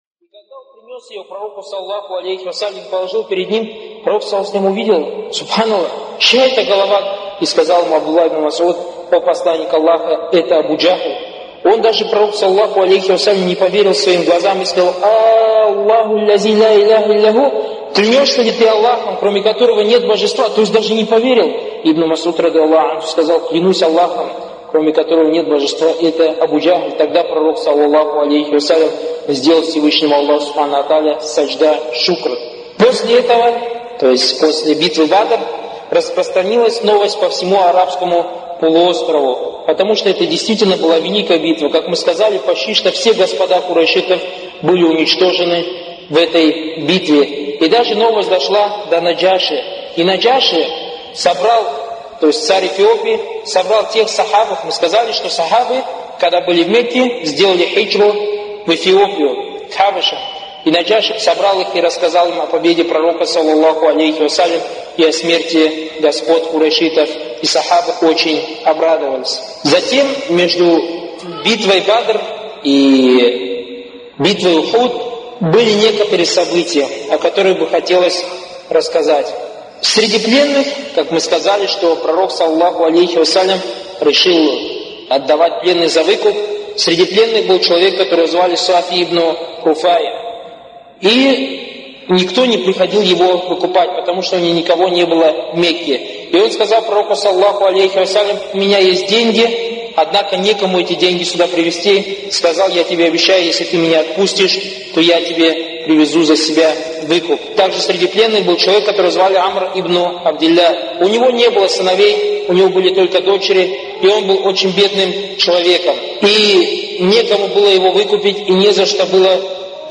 лекции Торик Суейдана (были приняты во внимание его ошибки, на которые указали учёные).